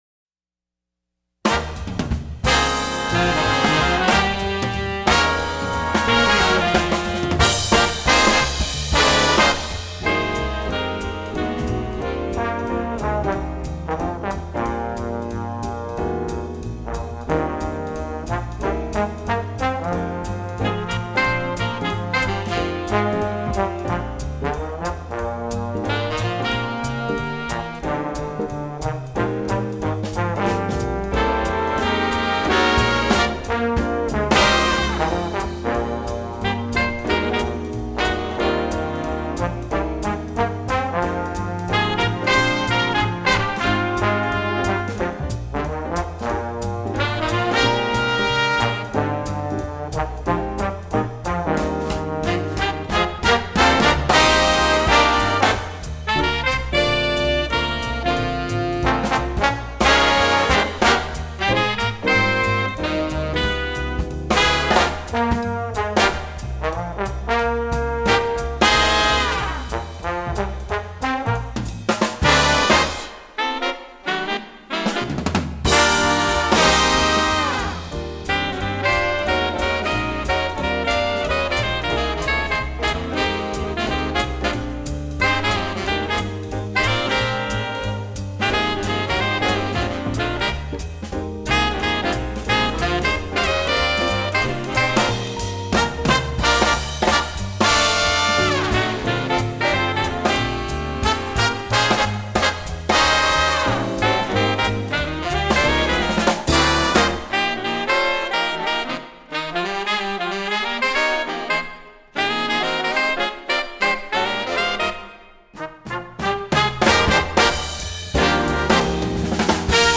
jazz, swing